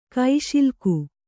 ♪ kaiśilku